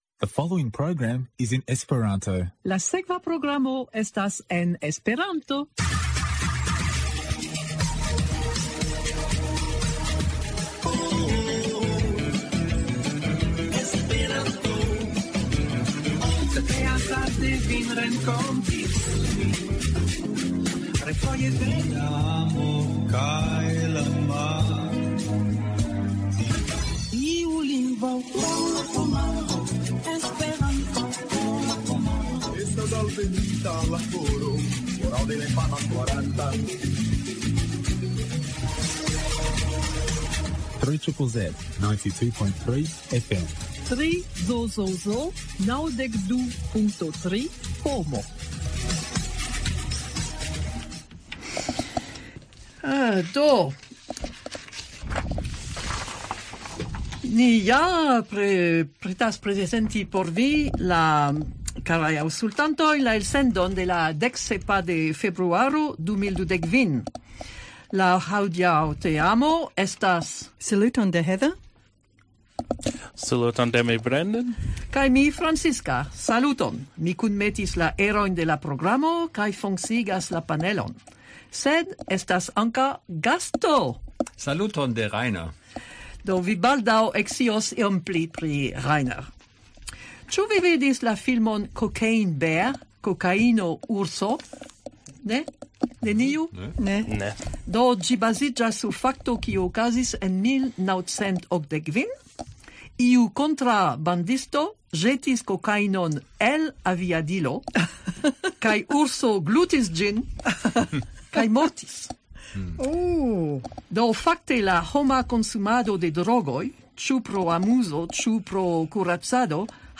En la Melburna Esperanto-komunumo estas teamo, kiu regule elsendas programon en Esperanto kadre de la komunuma radio 3ZZZ.
The Melbourne Esperanto community has a dedicated and enthusiastic group of people who produce an hourly Esperanto radio program each week.